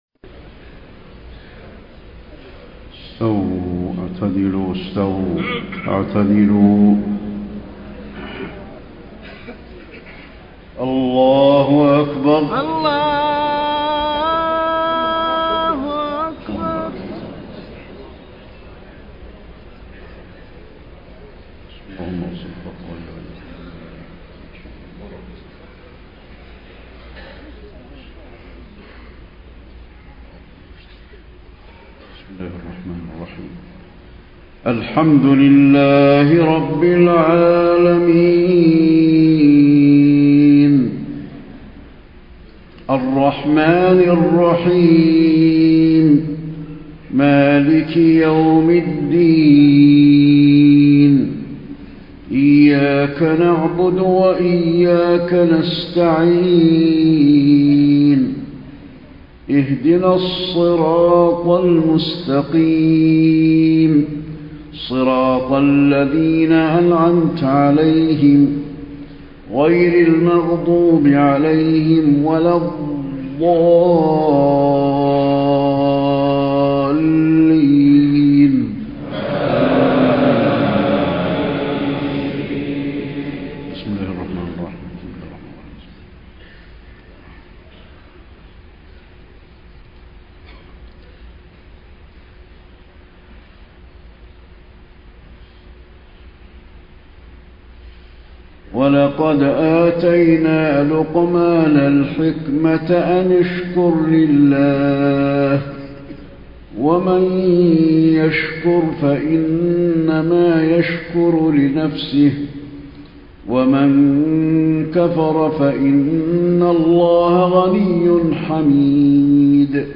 صلاة العشاء 5 - 4 - 1434هـ من سورة لقمان > 1434 🕌 > الفروض - تلاوات الحرمين